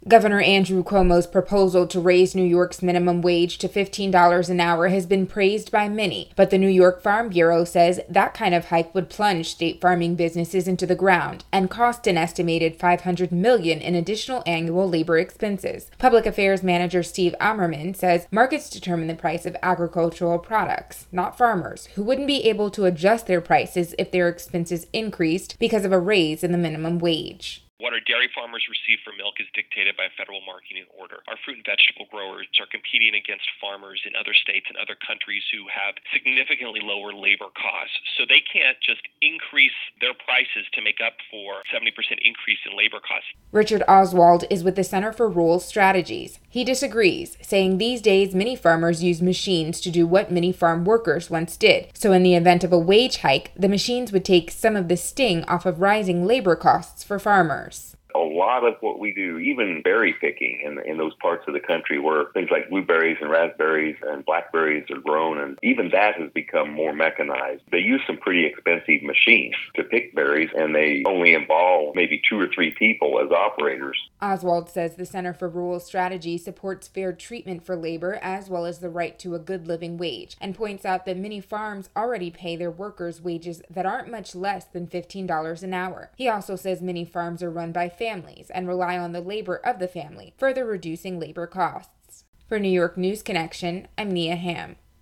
WGXC Evening News